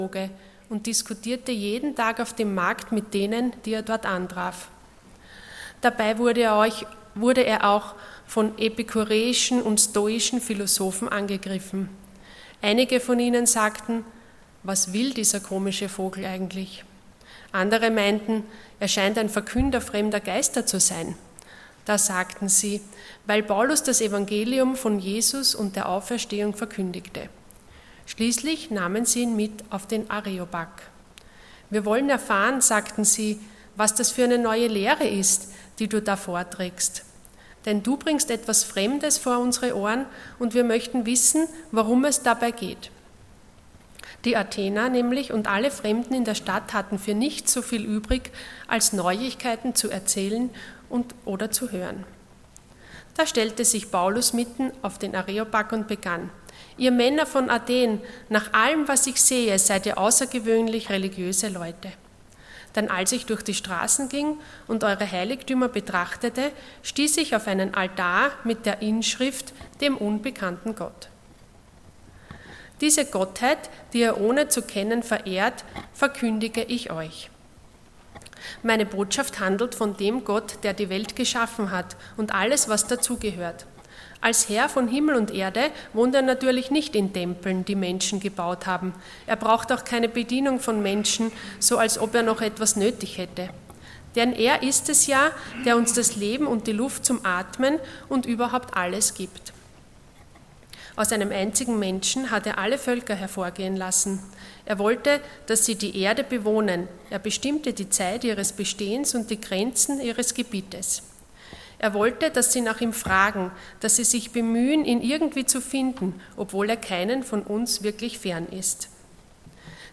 Ihr werdet meine Zeugen sein Passage: Acts 17:16-34 Dienstart: Sonntag Morgen %todo_render% Hast du Gott nach deinem Bild geschaffen?